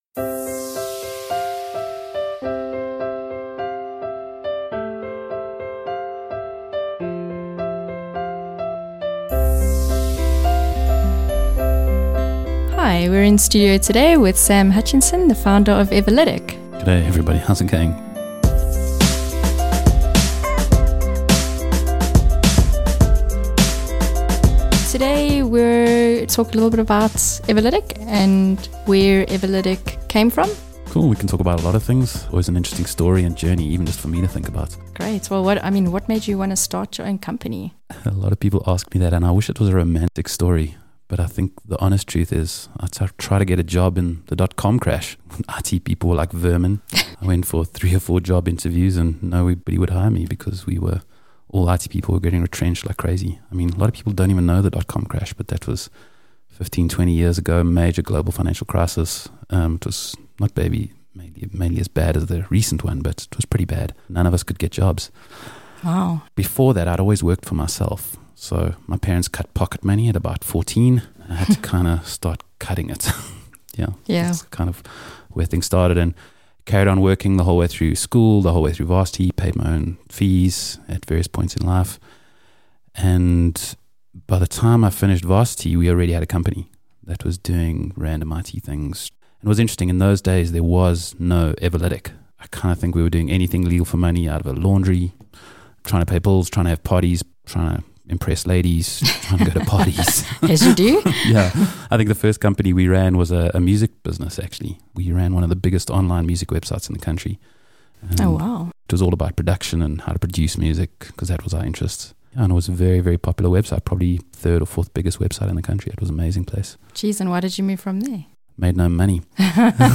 Audio Interview: How Everlytic was Born